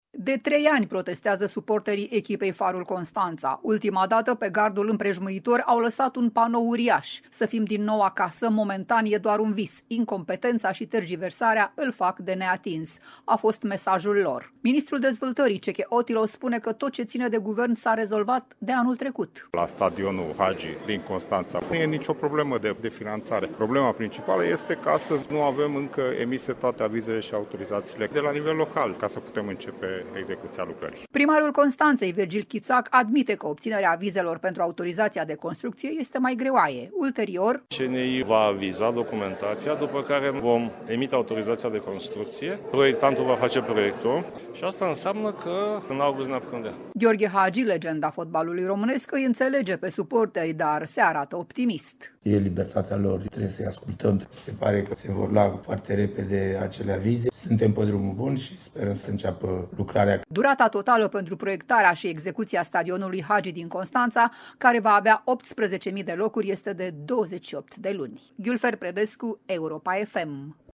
Ministrul Dezvoltării, Cseke Attila, spune că tot ce ține de Guvern s-a rezolvat de anul trecut:
Primarul Constanței admite că obținerea avizelor pentru autorizația de constructie e mai greoaie.
Gheorghe Hagi, legenda fotbalului românesc, îi înțelege pe suporteri, dar se arată optimist: